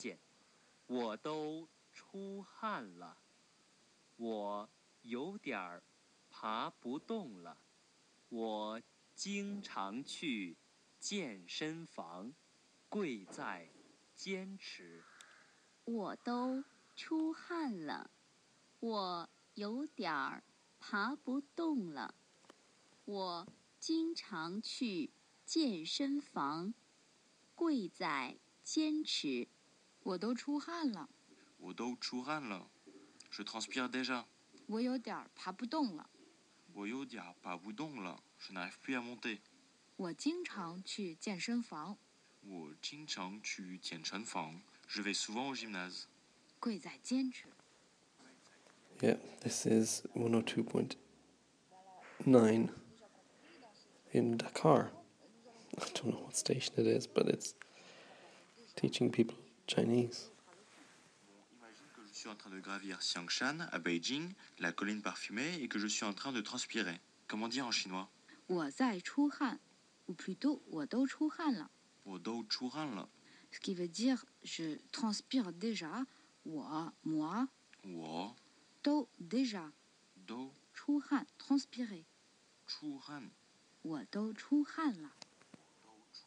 Mandarin lessons on the radio in Senegal